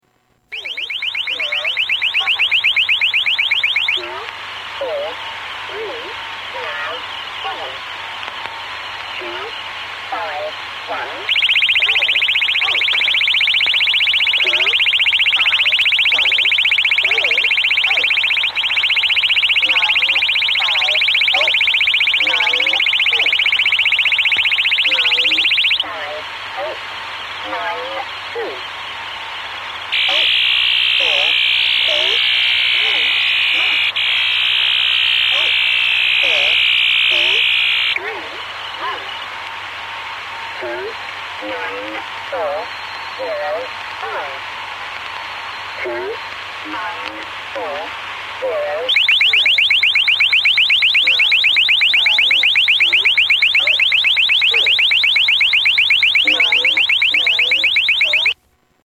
Iran Iraq Jamming Efficacy Test
Tags: radio